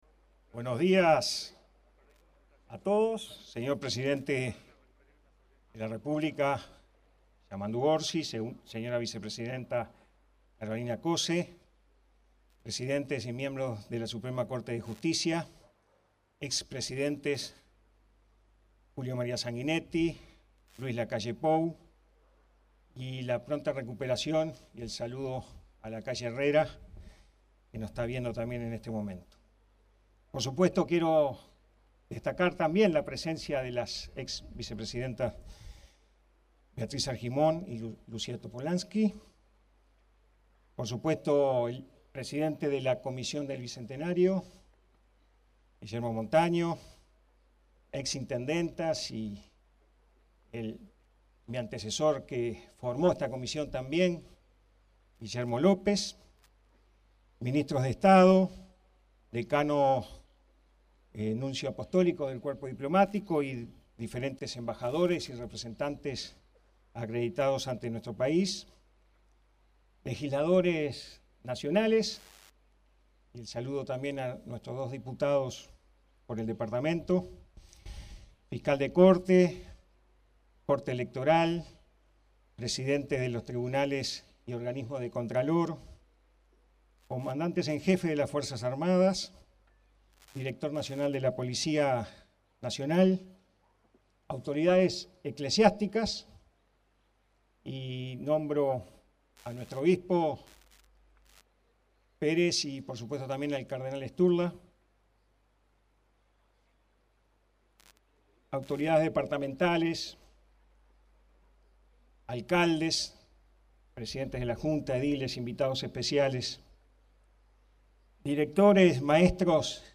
Palabras del intendente Carlos Enciso en aniversario de la Declaratoria de la Independencia
Este 25 de agosto, se celebró el 200 aniversario de la Declaratoria de la Independencia, definida como el inicio del proceso independentista de la